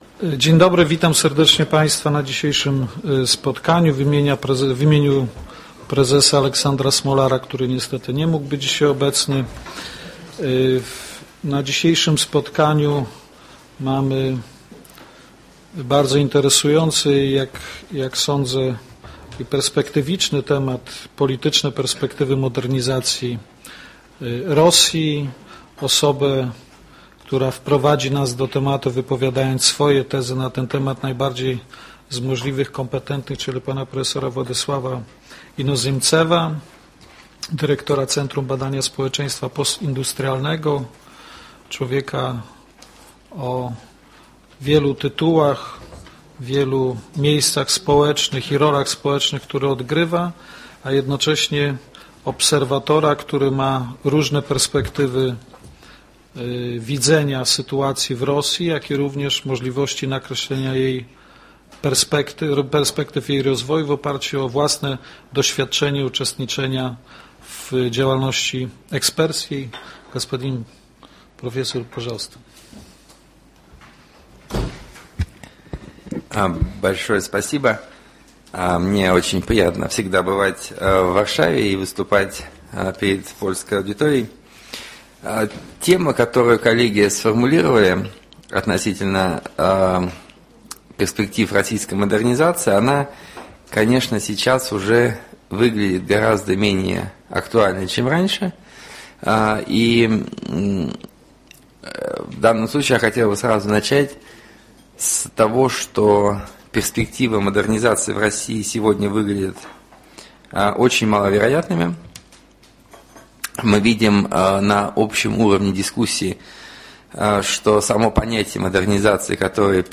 Stefana Batorego) Pełny zapis dźwiękowy debaty [MP3 47MB] , czas trwania: 2 godz. 14 min.